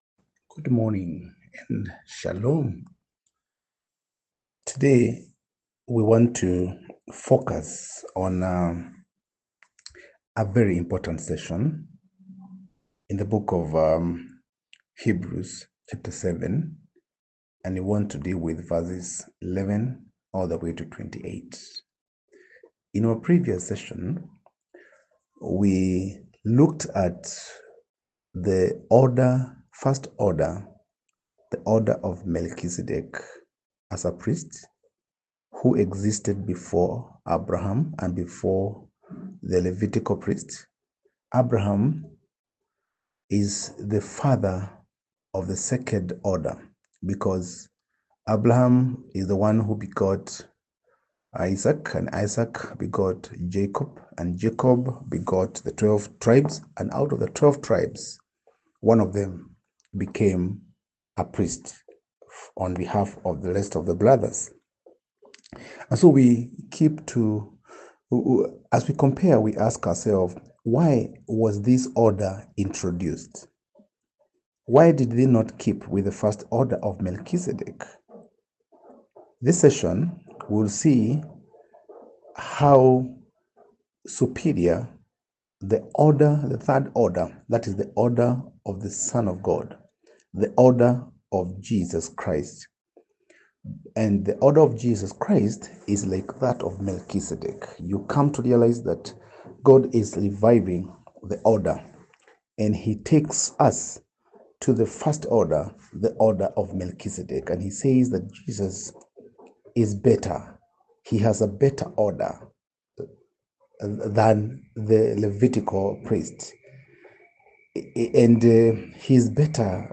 Post Lesson Teaching Summary Great job completing the study! Take a moment to listen to this summary to reinforce your group’s understanding of the text and ensure you’re all on the same page.